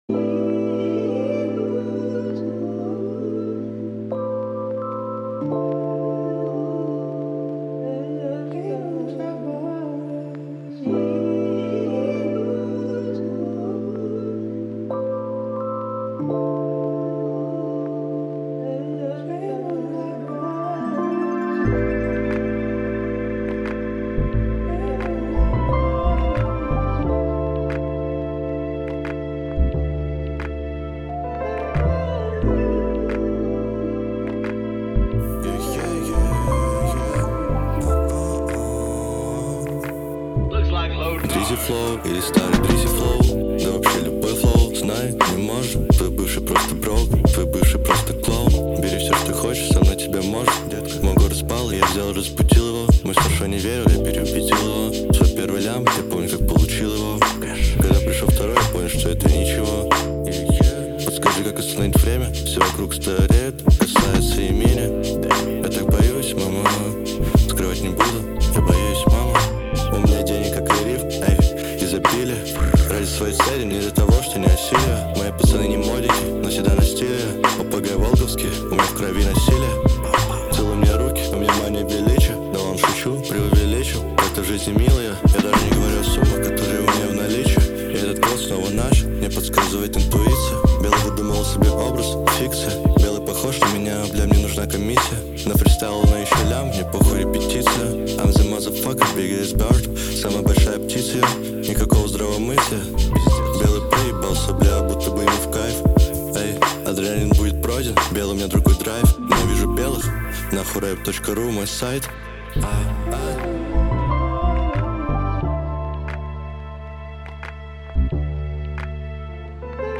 Русский Рэп 2025 Поделись Треком